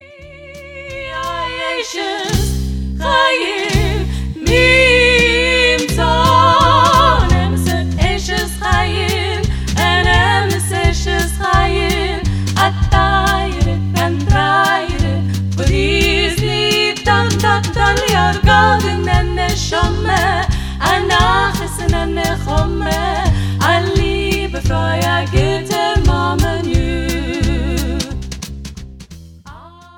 Beautiful melodies, powerful themes and perfect harmonies.